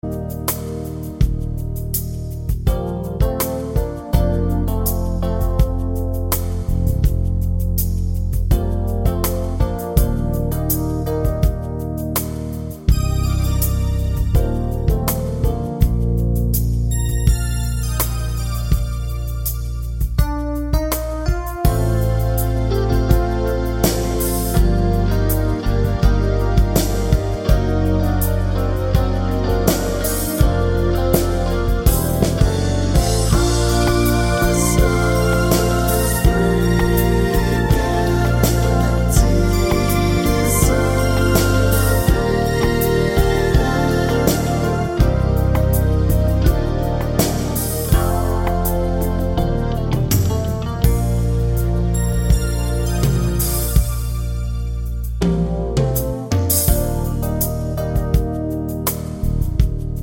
no Backing Vocals Soft Rock 4:23 Buy £1.50